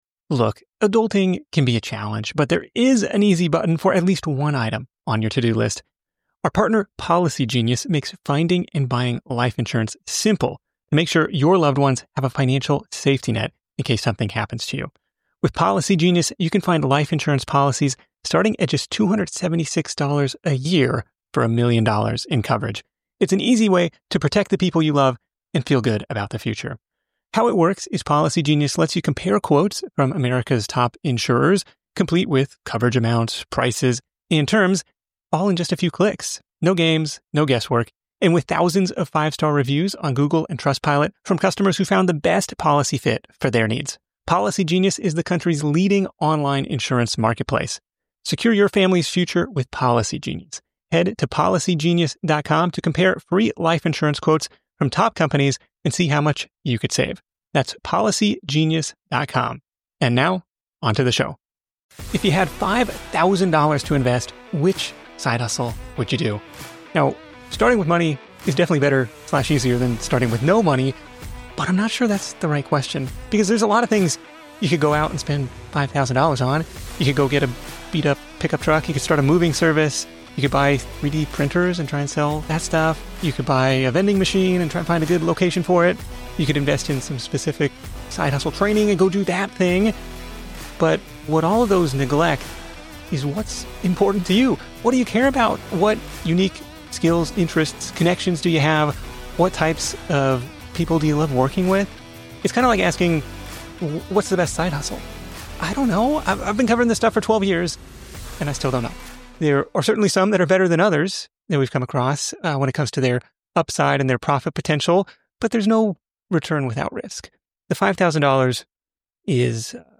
It’s that time again — back into the listener mailbag for another round of Q&A on The Side Hustle Show.